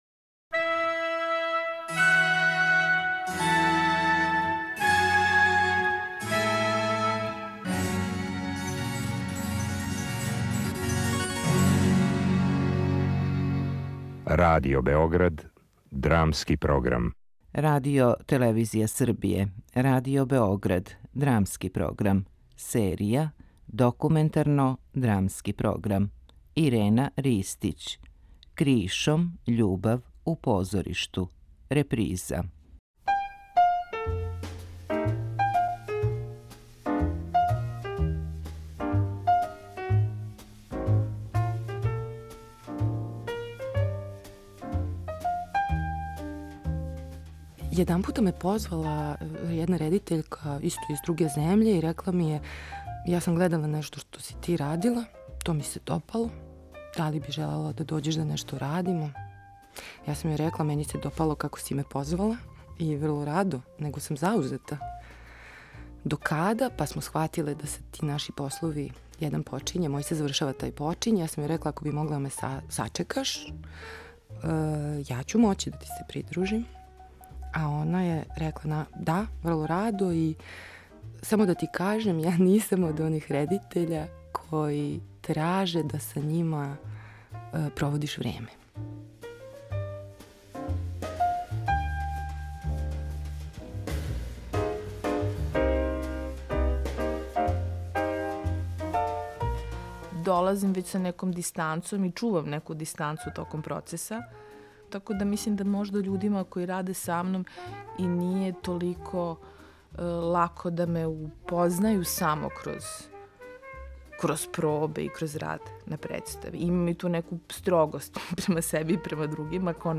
Документарно-драмски програм
Проблематизујући љубав и заљубљивање без обзира на родне улоге, све до питања хирерахије у процесу колективне креативности и настанка позоришне представе, у тихим интимним исповестима саговорници преиспитују ефемерност и крхкост насталих односа.